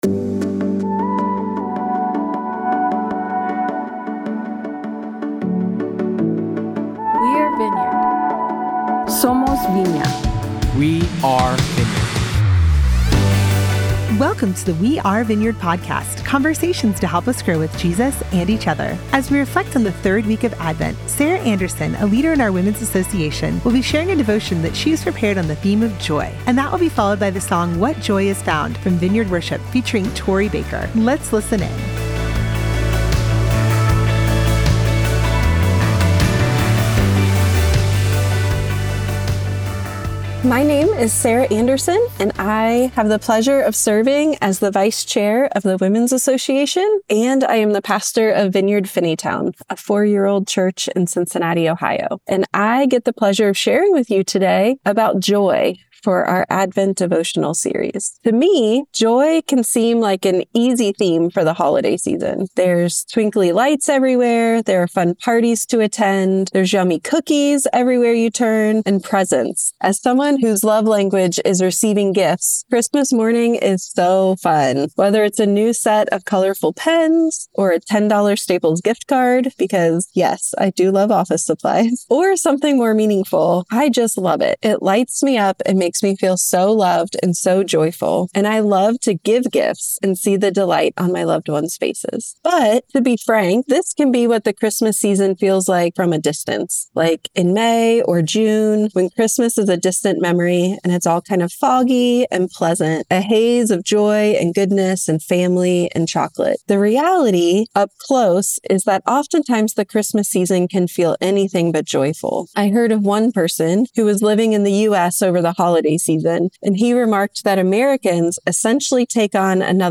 In celebration of the Advent season, you will hear a short devotion each week specially crafted for you by a member of one of Vineyard USA’s Associations.